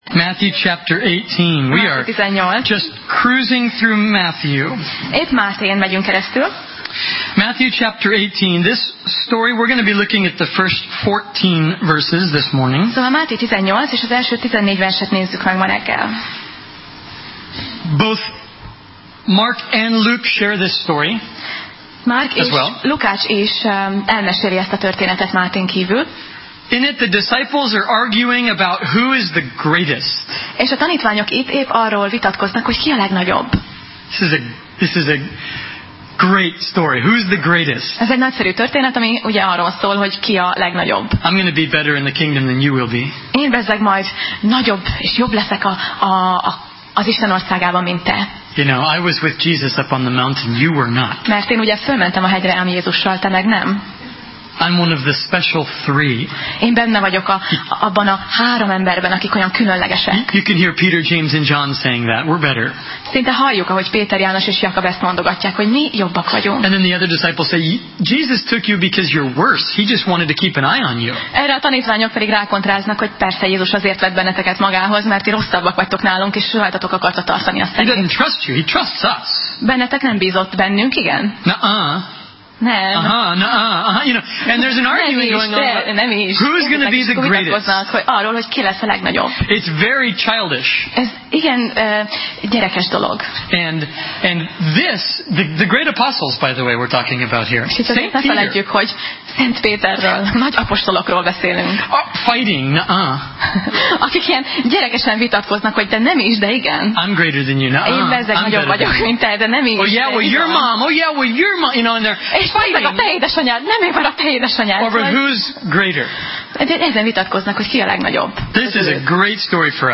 Máté Passage: Máté (Matthew) 18:1–14 Alkalom: Vasárnap Reggel